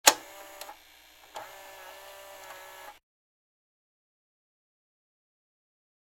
На этой странице вы найдете подборку звуков видеомагнитофона: характерное жужжание двигателя, щелчки кнопок, фоновые шумы аналоговой записи.
Звук зупинки на відеомагнітофоні